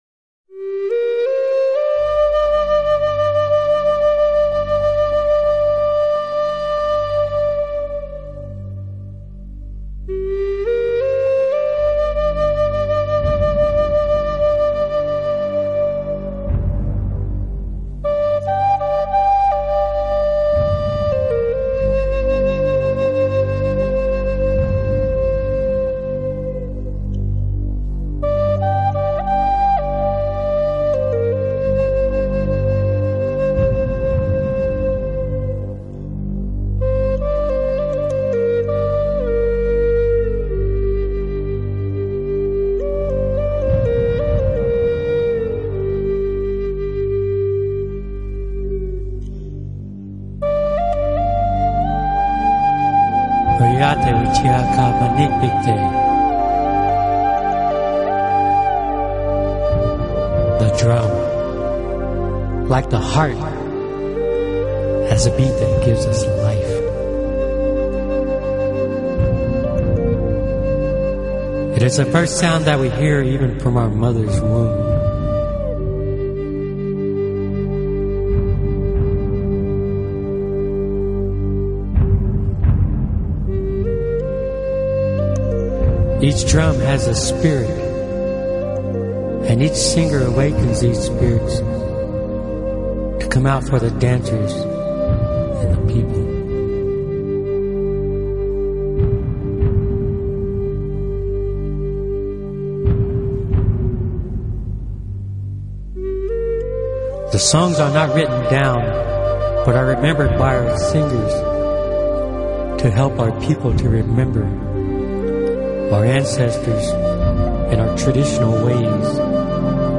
专辑语言：纯音乐
文化与族别，当代最具代表性的美洲原住民的音乐创作精华。